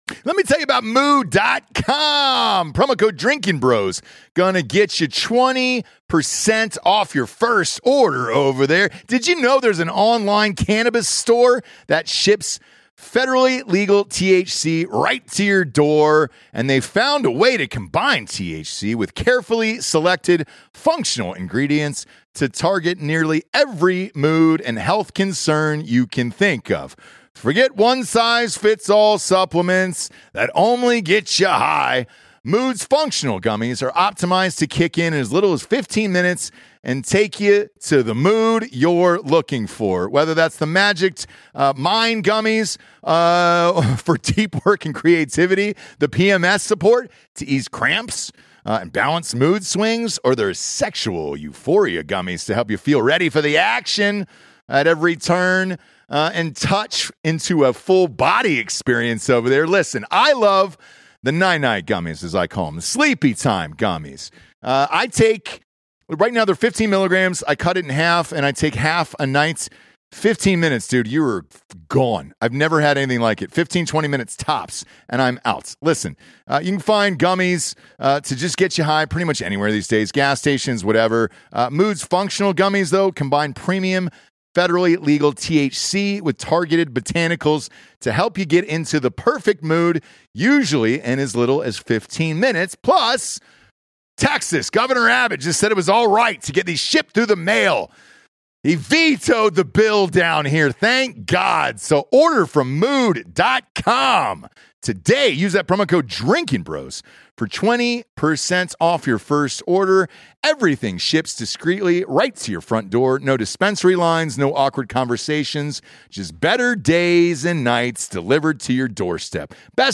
Comedian Jonathan Kite joins the show to talk about the new Anthony Bourdain book, SNL's massive cast turnover, doing voices on Family Guy, and Seth Rogen's people not being impressed by his Seth Rogen impression.